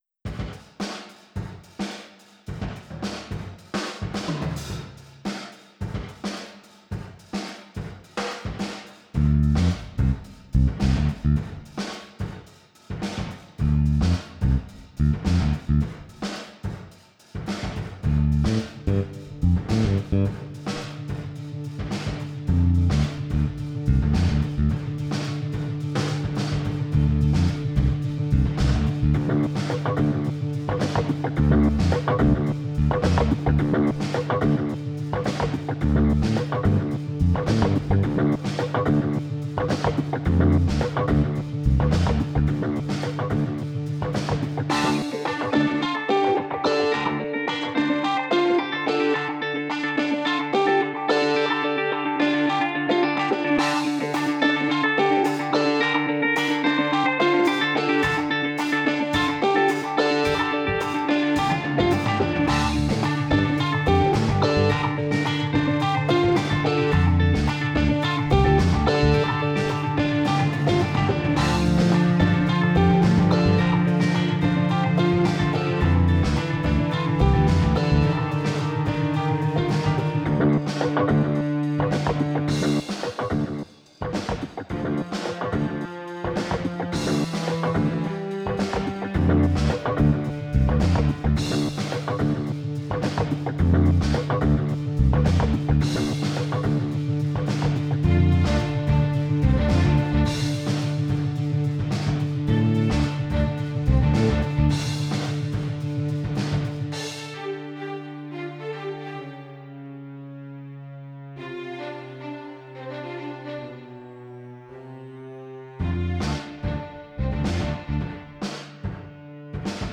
Tags: Strings, Guitar, Percussion
Title Incinerator Opus # 533 Year 2021 Duration 00:02:55 Self-Rating 3 Description Quick little driving thing. mp3 download wav download Files: wav mp3 Tags: Strings, Guitar, Percussion Plays: 188 Likes: 30